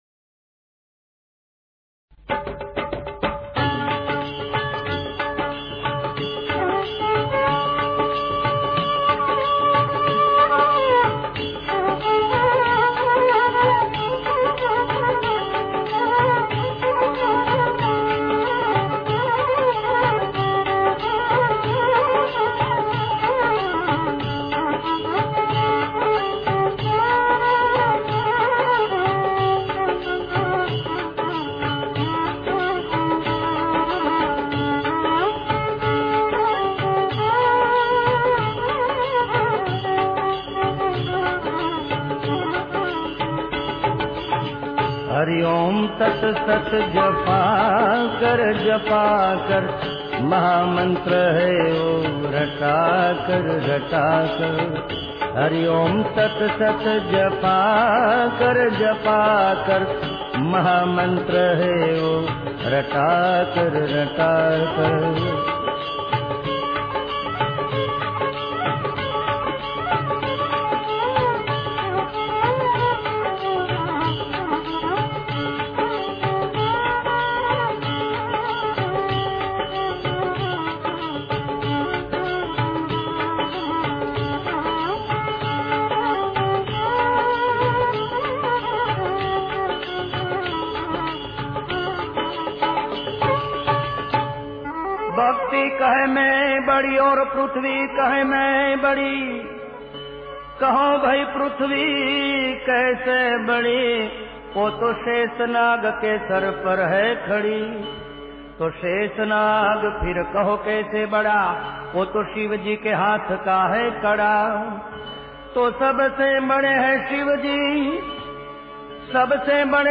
સંતવાણી